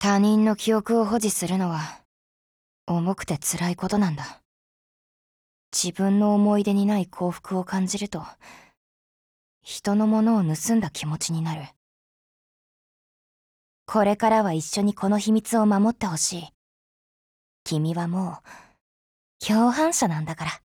贡献 ） 协议：Copyright，其他分类： 分类:SCAR-H 、 分类:语音 您不可以覆盖此文件。